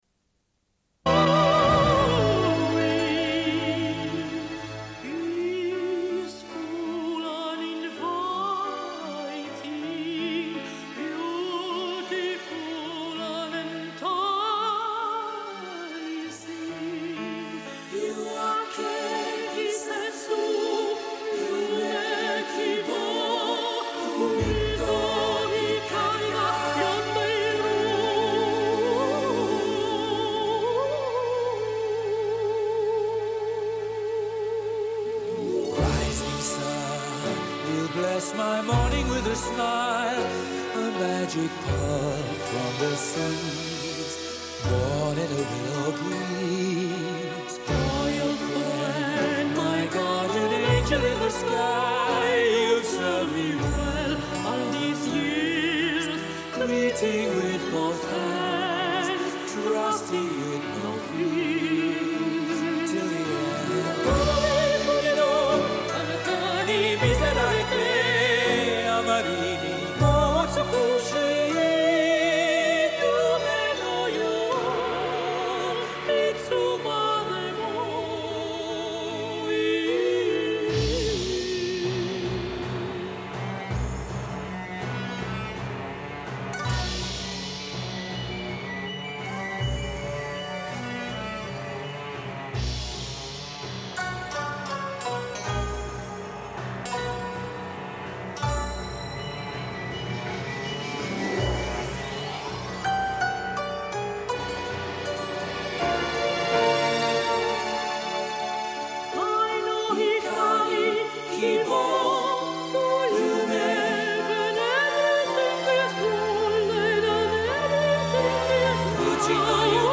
Une cassette audio, face A31:28